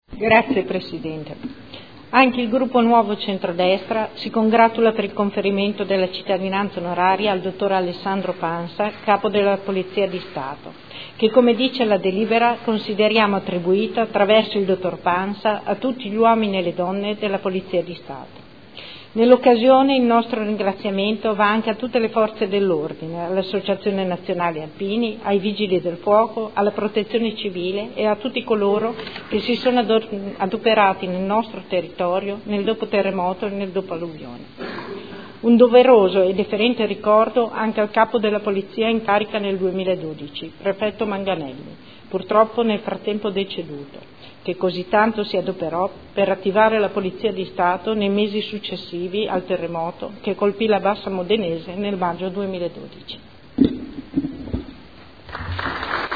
Seduta del 21/09/2015.